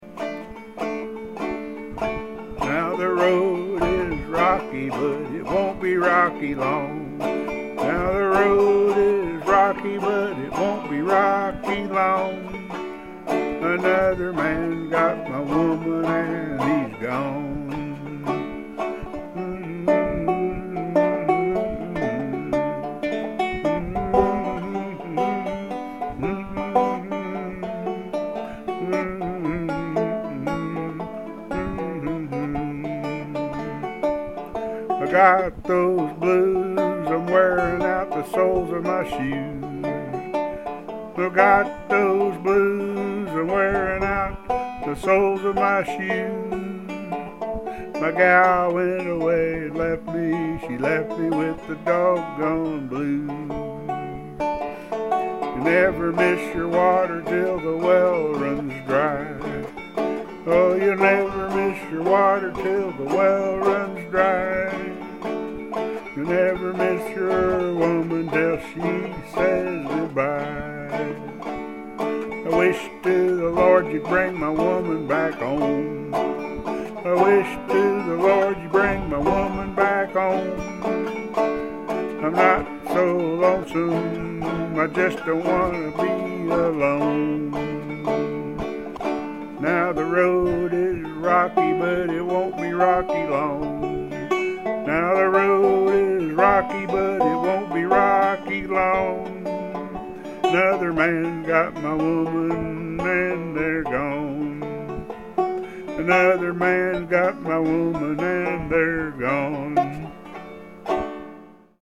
The cd is in D tho.